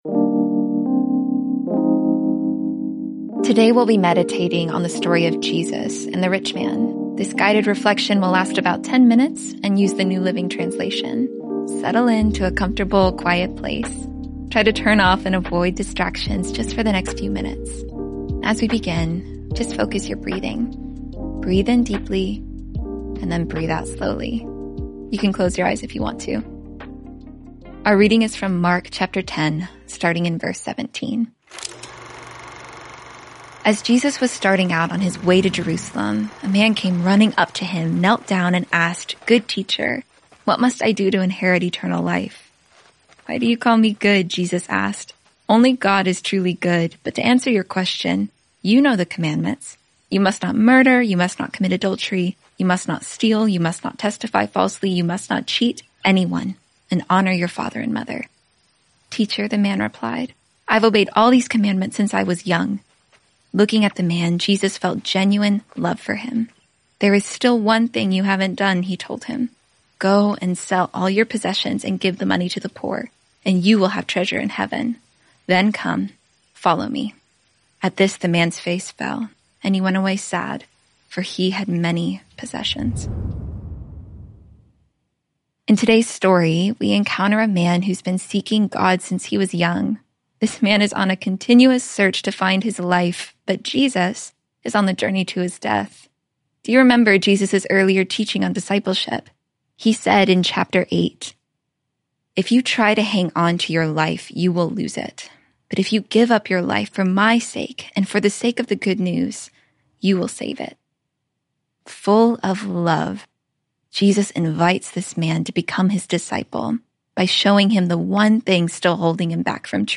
Today we’ll be meditating on the story of Jesus and the rich man. This guided reflection will last about ten minutes and use the New Living Translation.